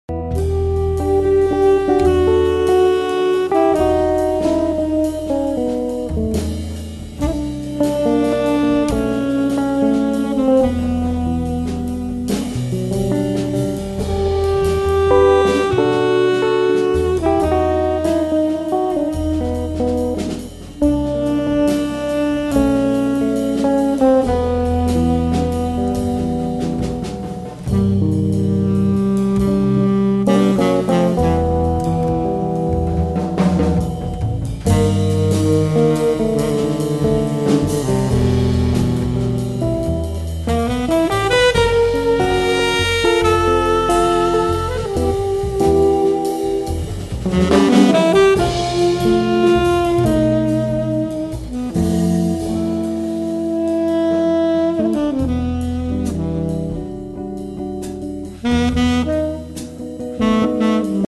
guitar
double bass
soprano and tenor sax
drums